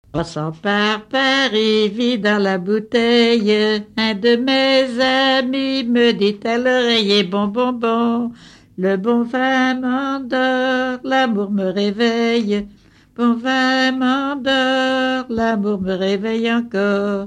en duo
Pièce musicale inédite